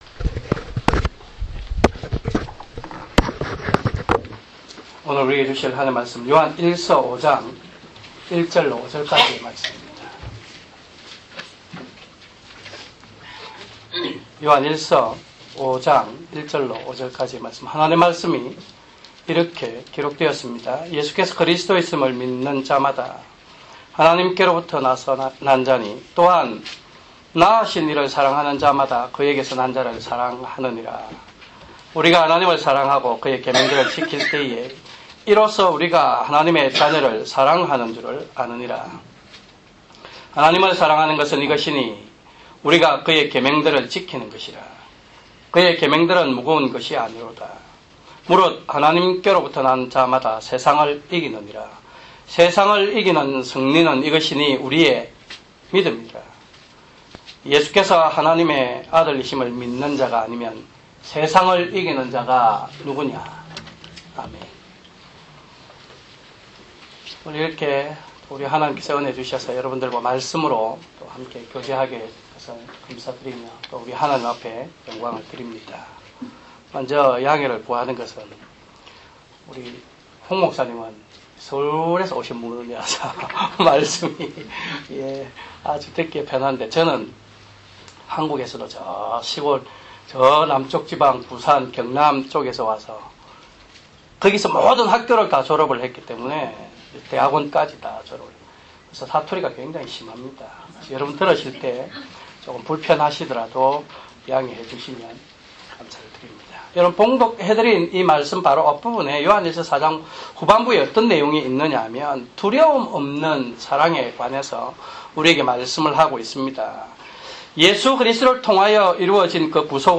주일 설교] 요한일서5:1-5